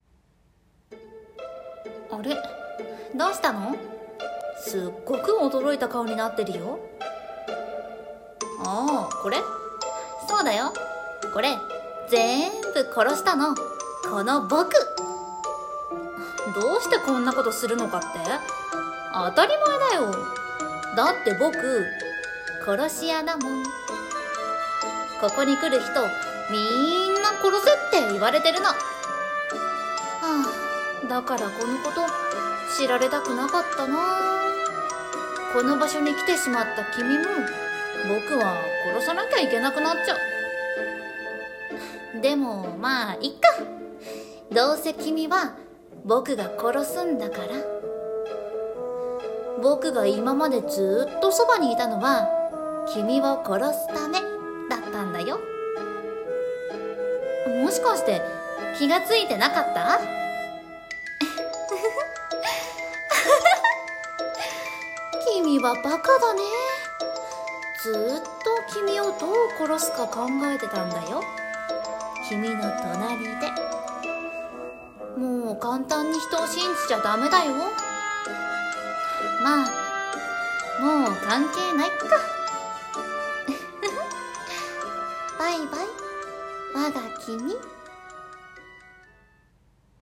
【声劇】 殺し屋のアナタ